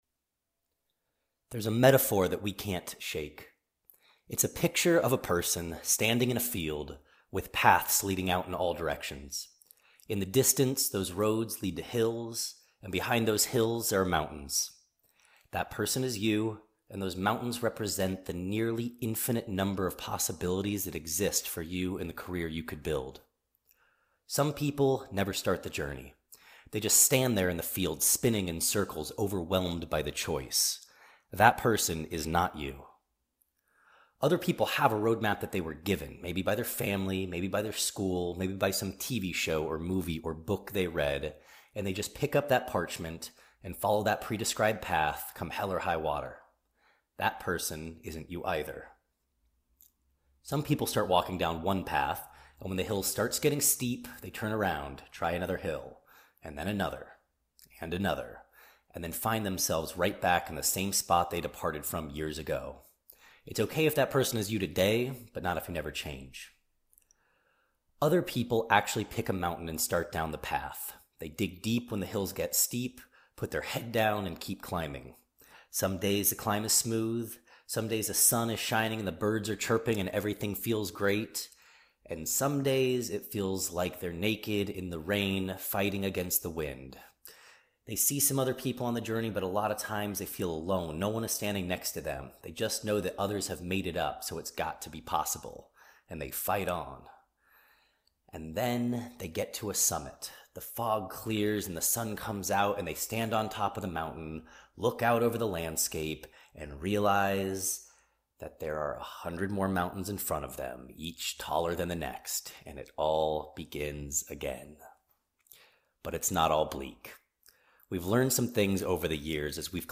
When you’re ready, press play on the audio file and we’ll walk you through a guided visualization that is about 15 minutes long, but asks you to start and stop to give you plenty of time to dream.